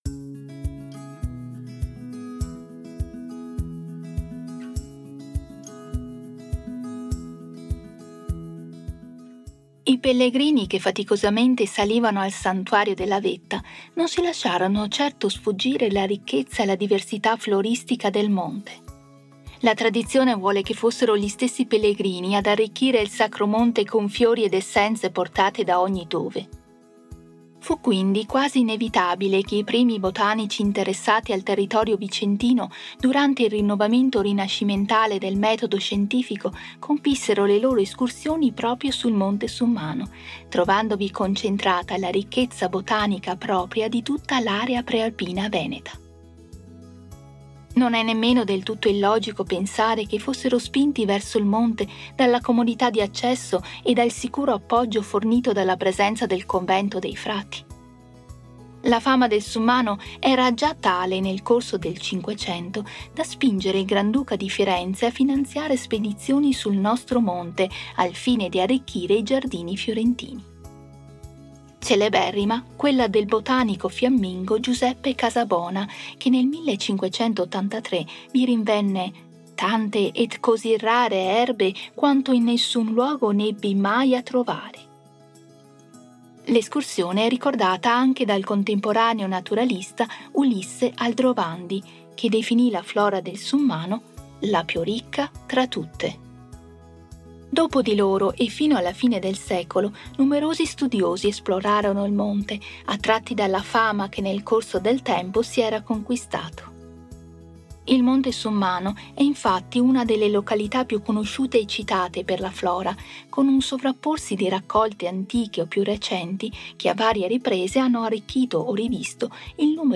AUDIOGUIDA_Girolimini._10._Fiori_e_botanici_illustri.mp3